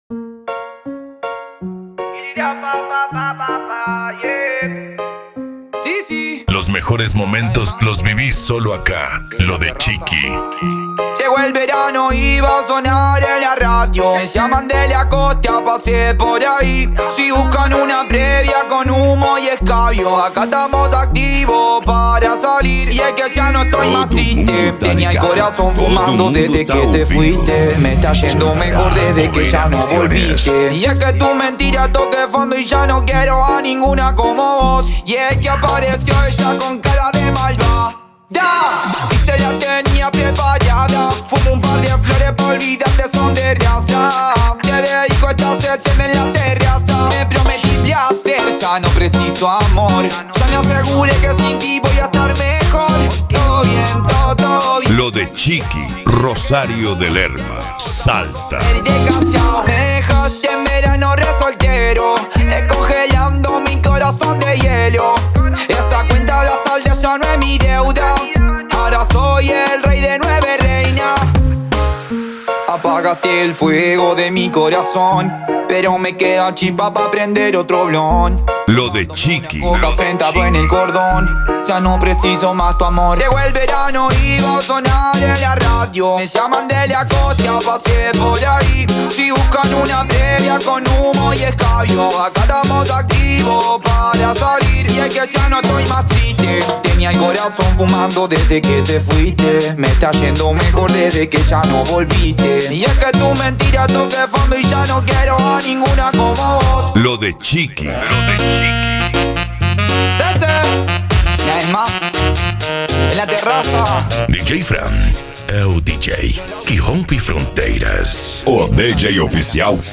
Remix
Retro Music